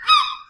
Seagull 001.wav